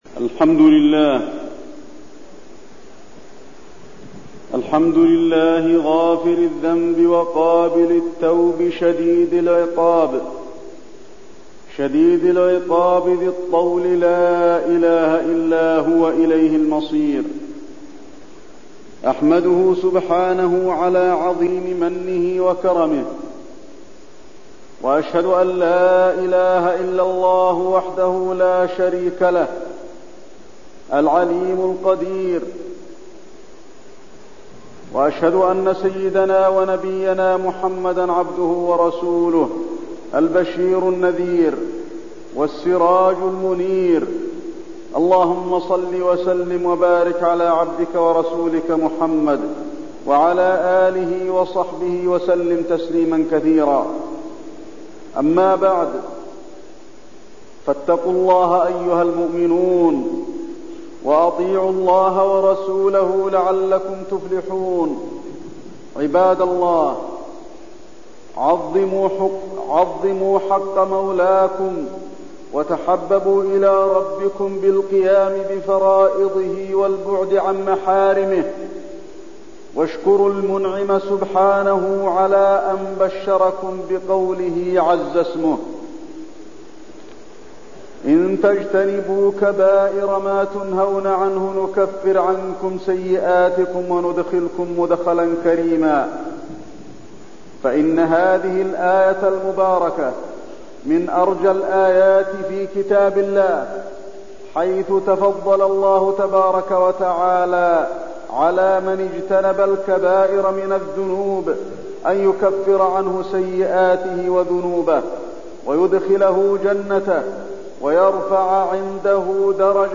تاريخ النشر ٢٠ شوال ١٤٠٦ هـ المكان: المسجد النبوي الشيخ: فضيلة الشيخ د. علي بن عبدالرحمن الحذيفي فضيلة الشيخ د. علي بن عبدالرحمن الحذيفي الكبائر